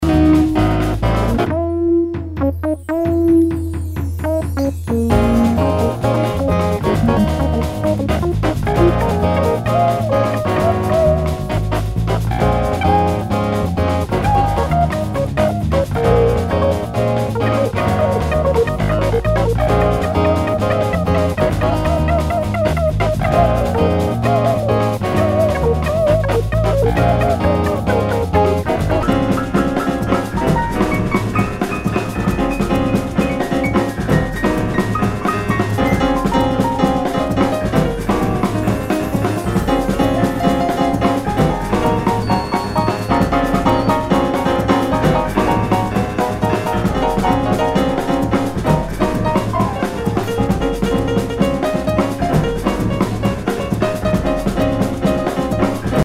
Nu- Jazz/BREAK BEATS
ナイス！アフロ / アシッド・ジャズ！